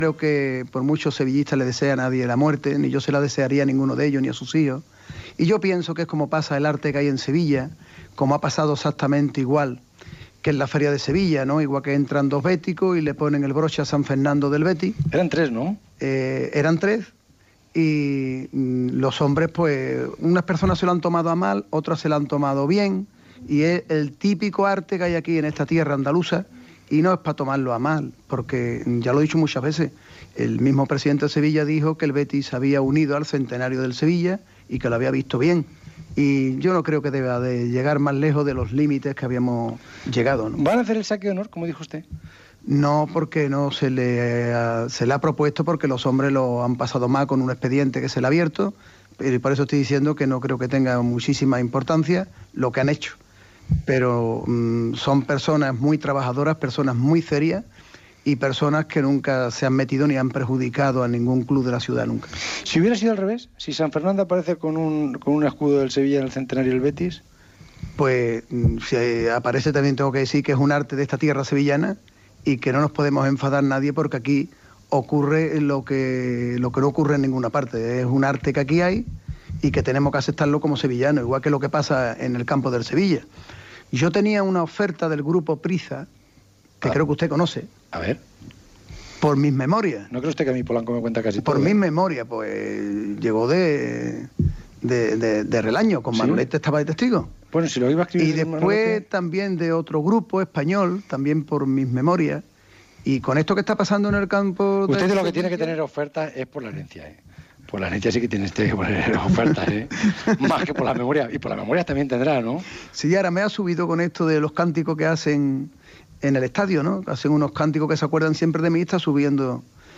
Preguntes a ciutadans del barri de Nervión de Sevilla i de Triana. Publicitat i indicatiu Gènere radiofònic Esportiu